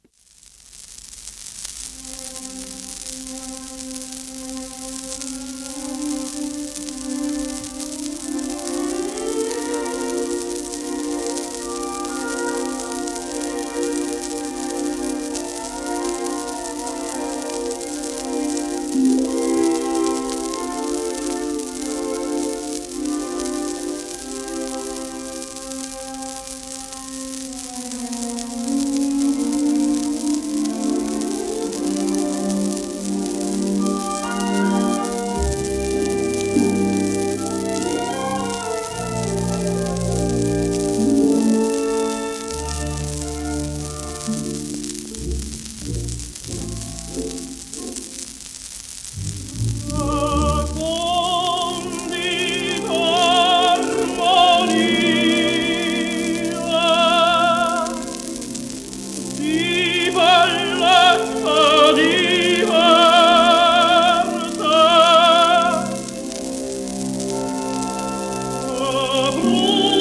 1950年頃録音
クラシックのSPレコードとしては終わりの頃の録音プレスになります